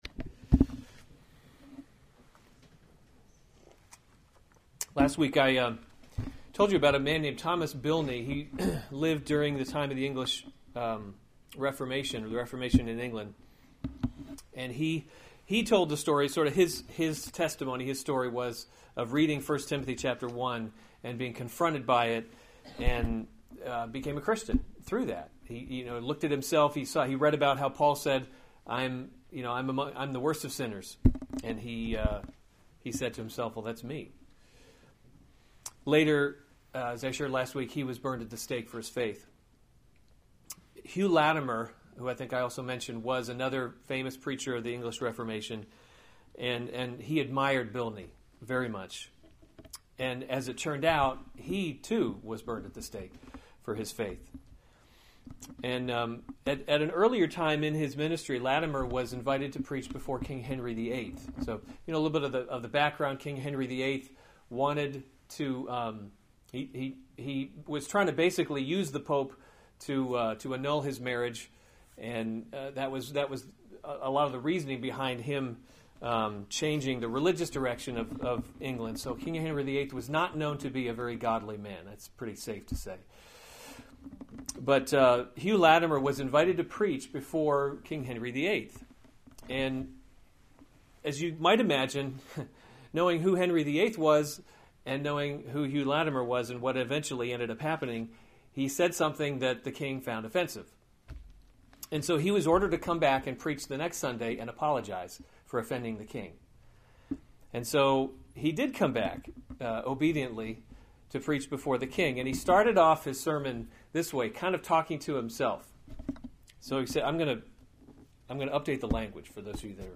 February 25, 2017 1 Timothy – Leading by Example series Weekly Sunday Service Save/Download this sermon 1 Timothy 1:18-20 Other sermons from 1 Timothy 18 This charge I entrust to you, […]